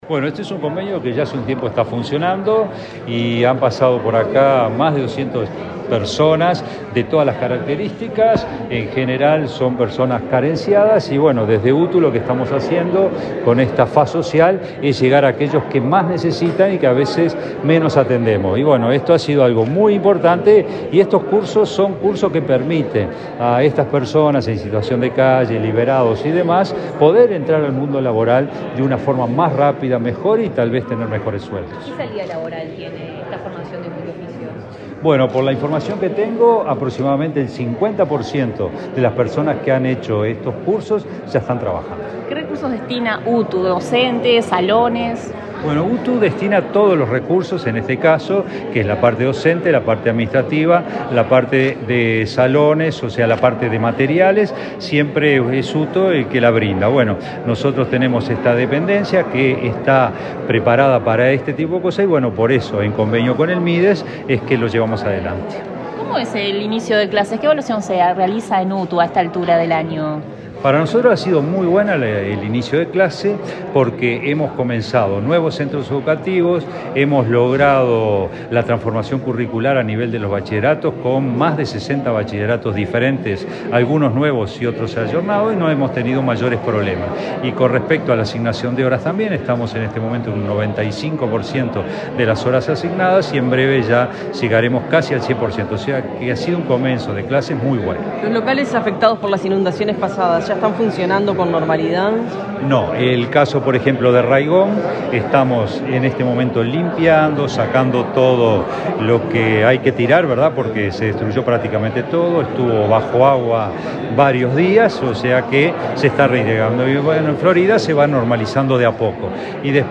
Declaraciones del director general de UTU, Juan Pereyra
El director general de UTU, Juan Pereyra, participó, este lunes 8, en Montevideo, del lanzamiento de la novena edición de los cursos multioficios